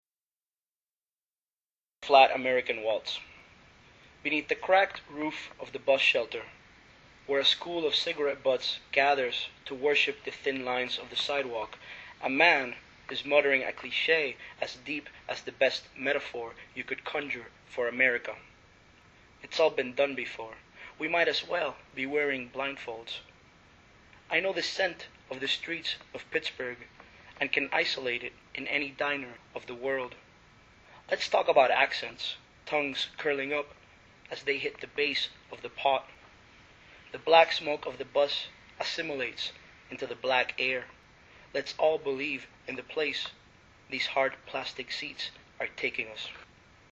recorded live at Bowdoin College, Brunswick, Maine,
Flat_American_Waltz_live.mp3